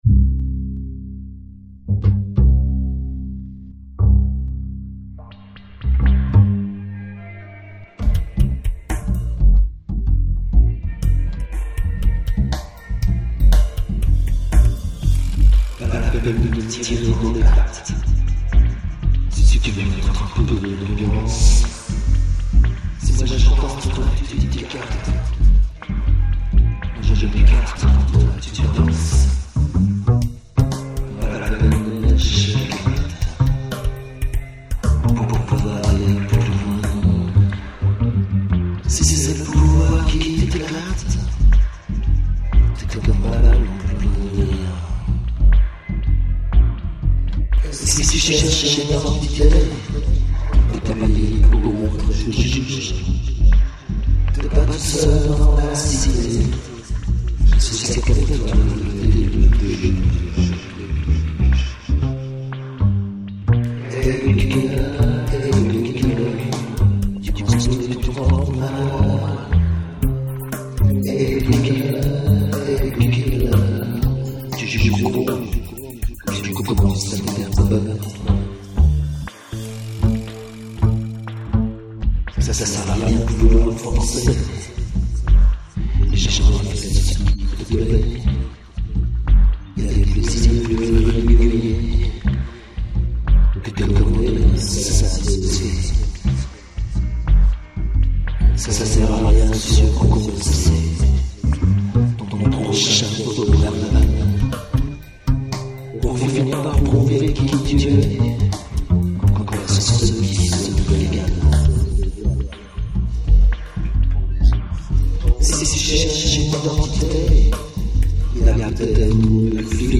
(maquette version ultra-sobre avec la basse)